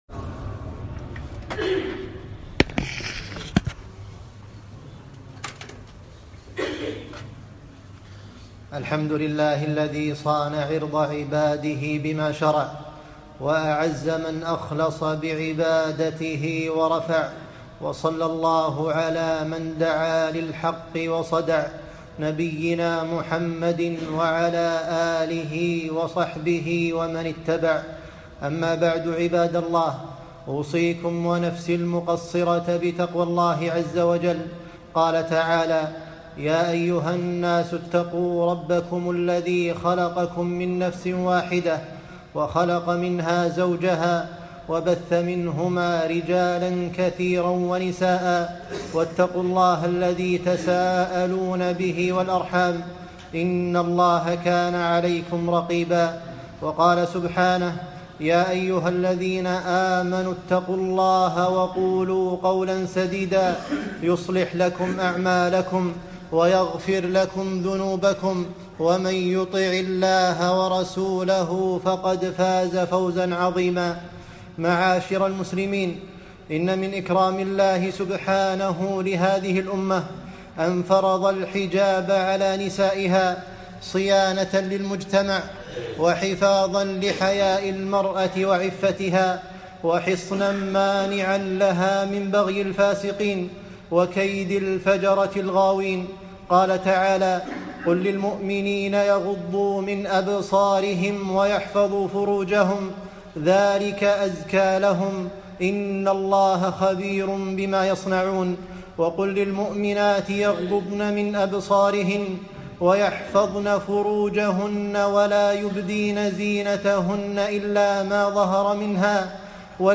خطبة - حجاب المرأة المسلمة